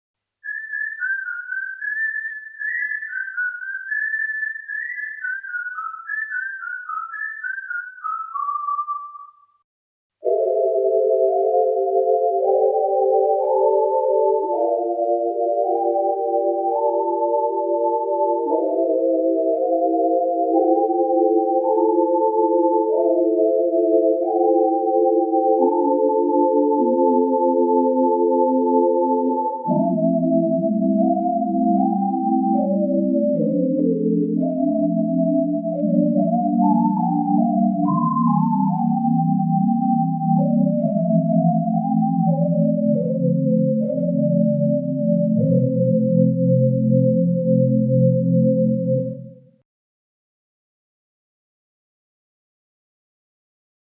Tous ces sons ont été enregistrées directement en sortie du DX7, donc sans aucun effet : ni reverb, ni chorus.
WaltDisney p.172 : sifflement humain, permettant des ambiances aériennes comme inquiétantes…